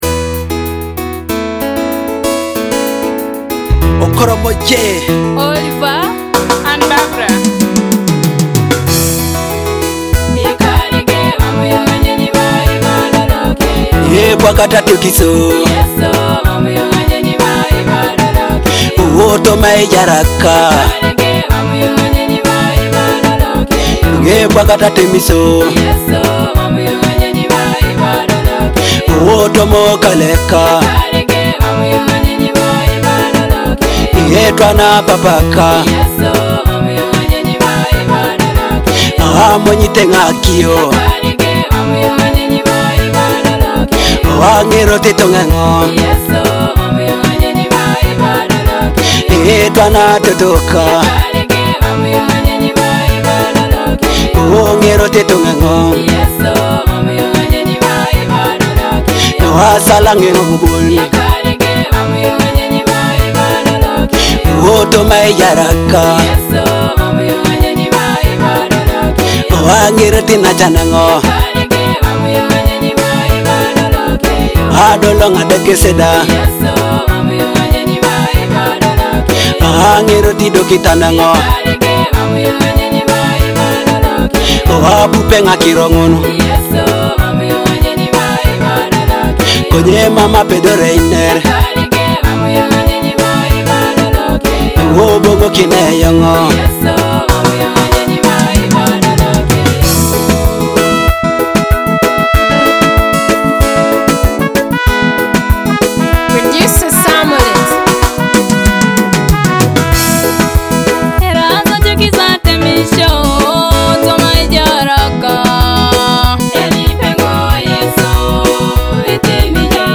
heartfelt prayer